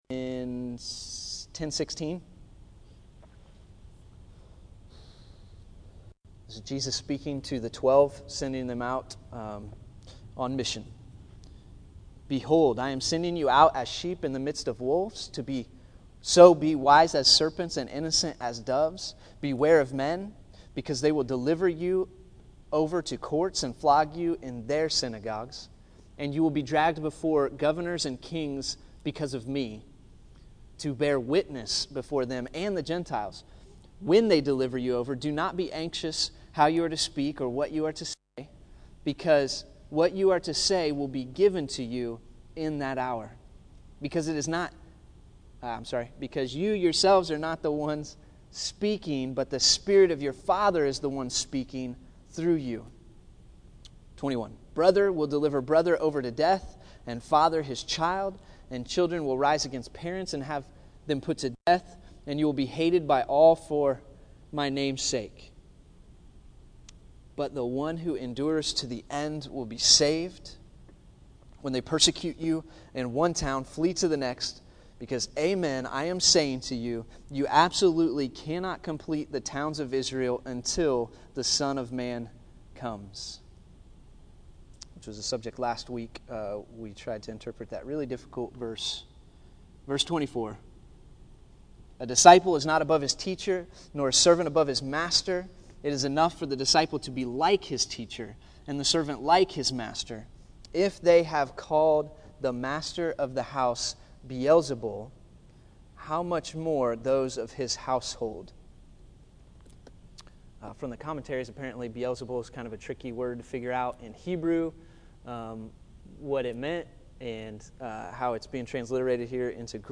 Matthew 10:21-42 July 07, 2013 Category: Sunday School | Location: El Dorado Back to the Resource Library Jesus undergirded the requirement to endure persecution as bold witnesses to the end with a warning of hell and a promise of reward at the final judgment. The ground of assurance He offered was the comprehensive and caring Providence of God.